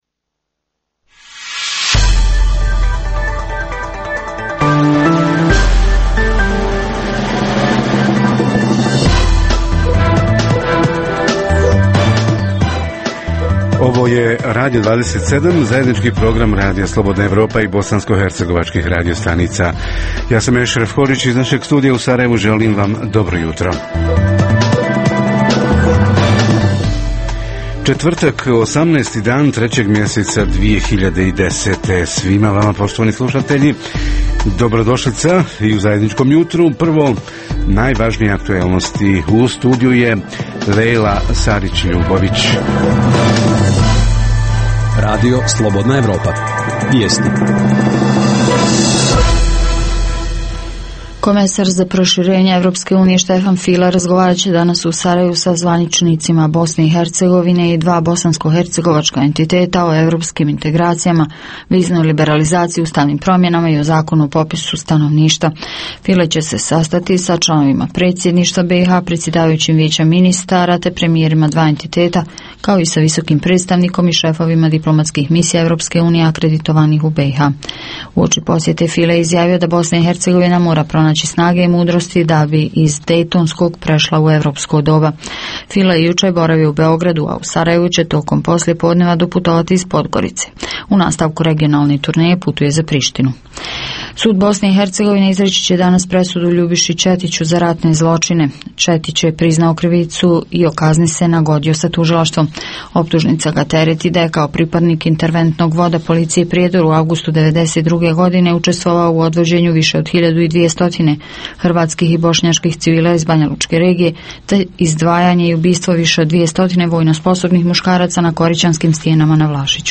Skloništa za slučaj ratnih dejstava i elementarnih nepogoda – u kakvom su stanju postojeća, ko i kako o njima brine i priprema li se izgradnja novih skloništa? Reporteri iz cijele BiH javljaju o najaktuelnijim događajima u njihovim sredinama.
Redovni sadržaji jutarnjeg programa za BiH su i vijesti i muzika.